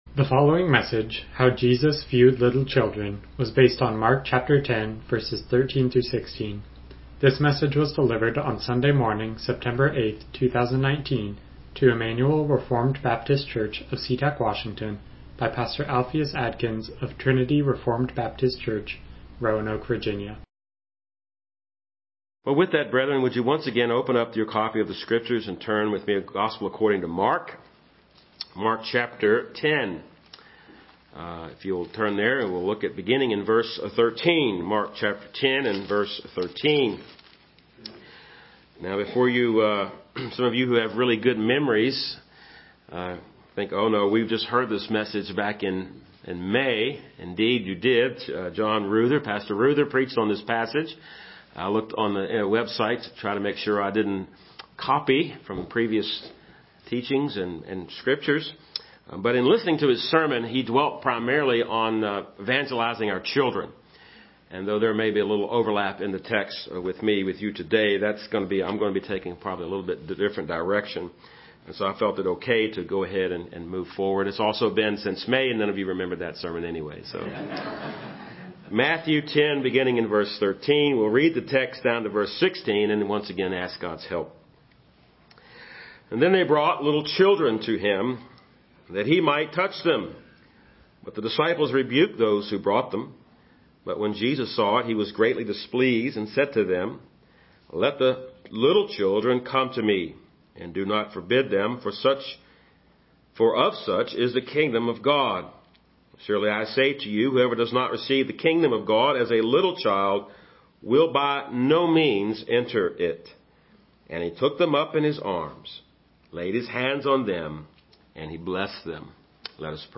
Mark 10:13-16 Service Type: Morning Worship « Blessed Are the Merciful Timothy